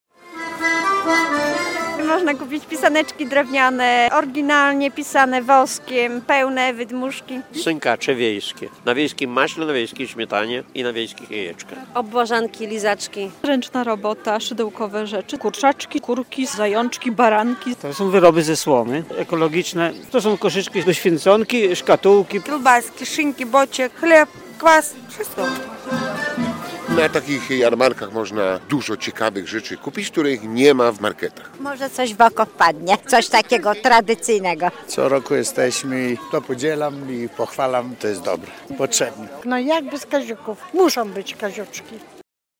Gwarno, kolorowo i smakowicie było w niedzielę (02.03) na ulicy Patli w Suwałkach.